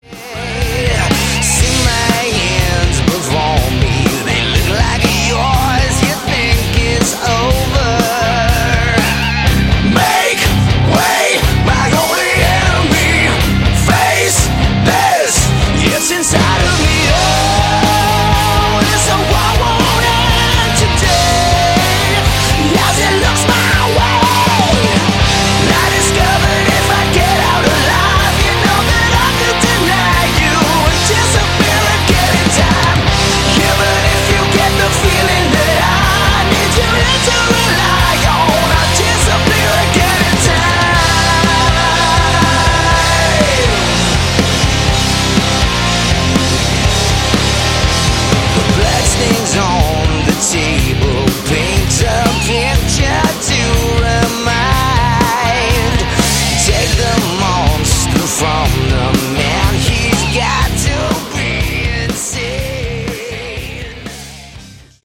Category: Modern Hard Rock
vocals
guitars
bass
drums